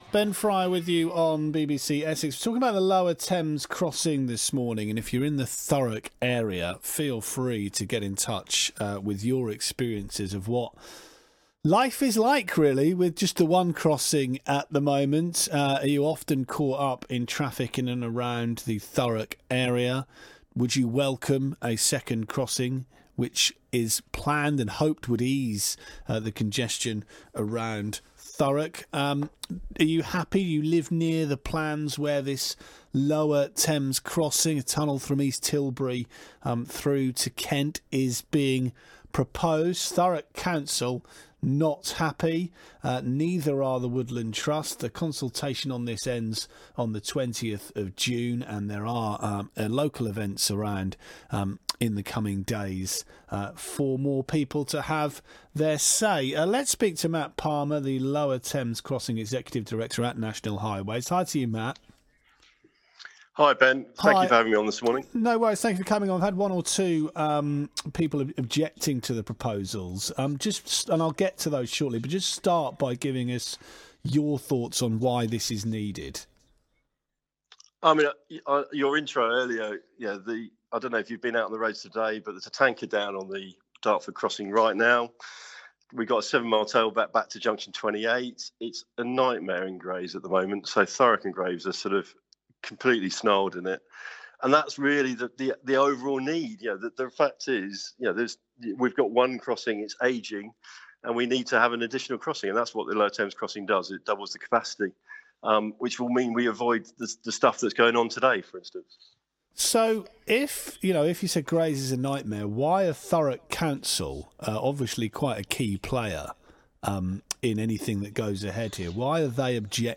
LTC discussion on BBC Essex Radio